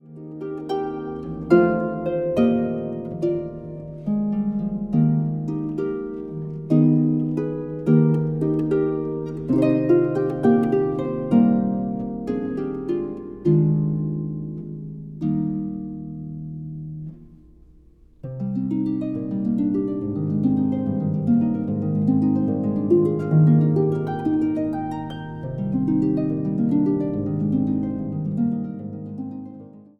Instrumentaal | Harp